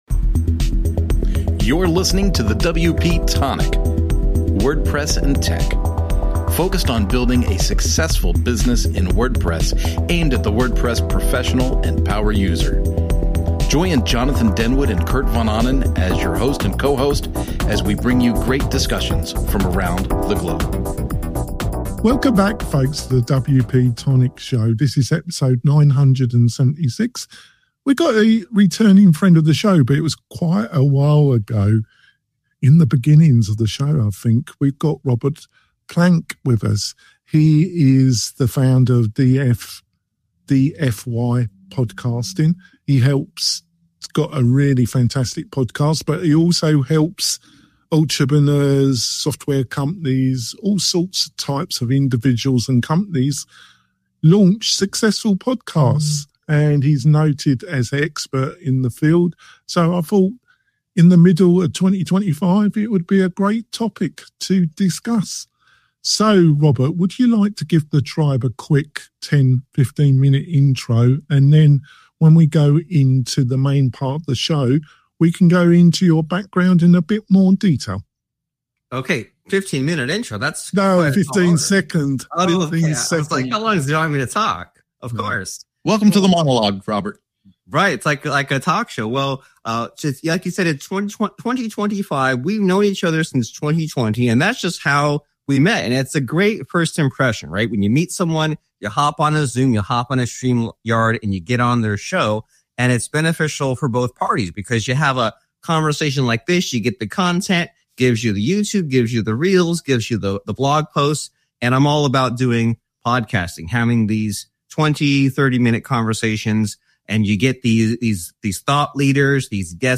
We interview some creative WordPress and startup entrepreneurs plus online experts who, with their insights, can help you build your online business.